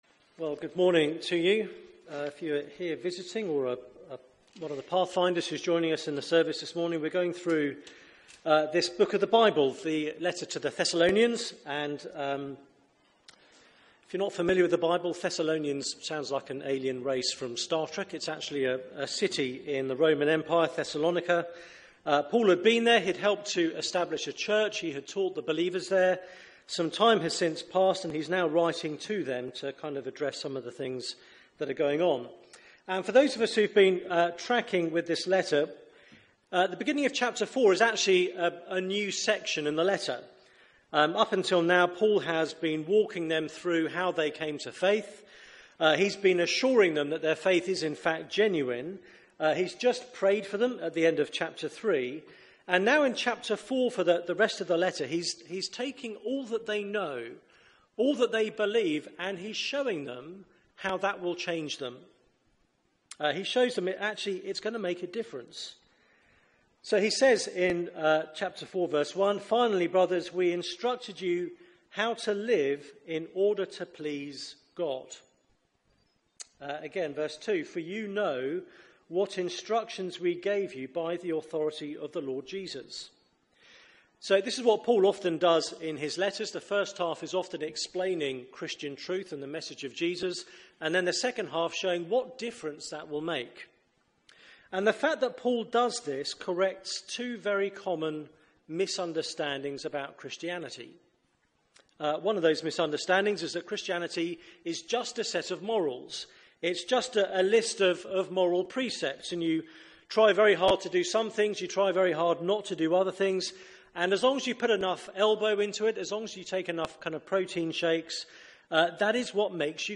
Media for 9:15am Service on Sun 11th Jun 2017